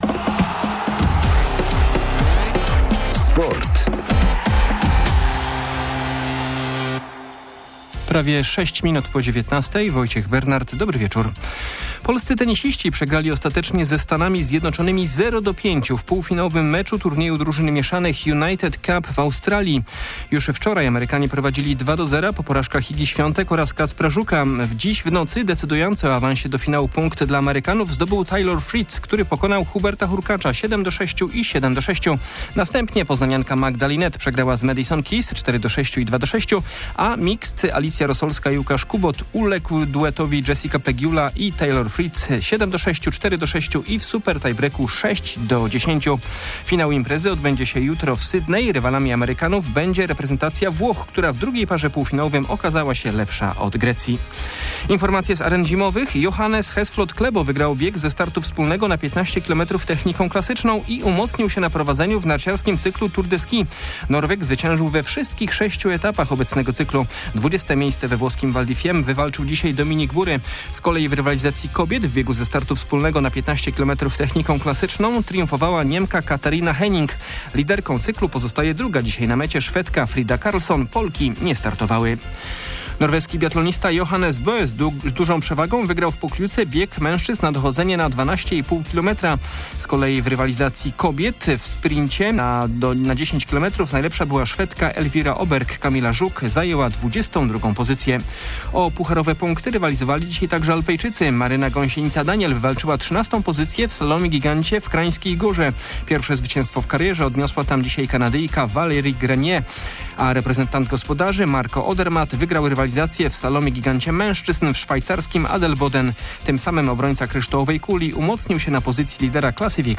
07.01.2023 SERWIS SPORTOWY GODZ. 19:05